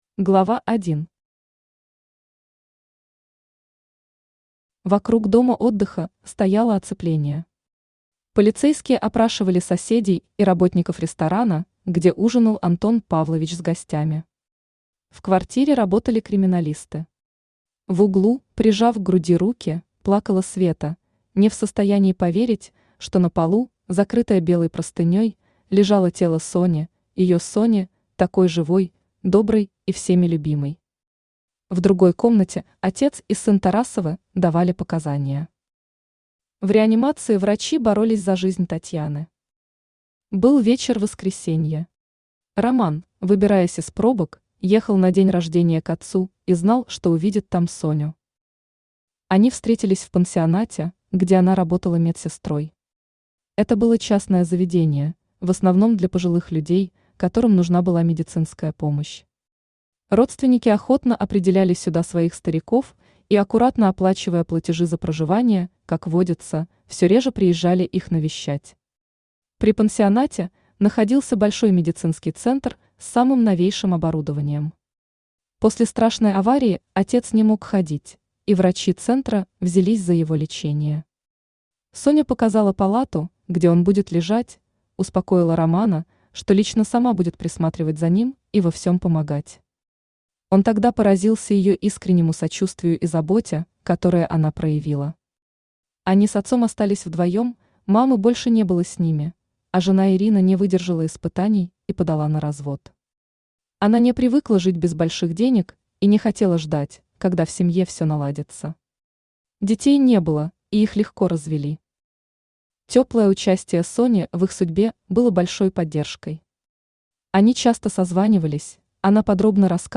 Аудиокнига Солнечная Лань | Библиотека аудиокниг
Aудиокнига Солнечная Лань Автор Светлана Критская Читает аудиокнигу Авточтец ЛитРес.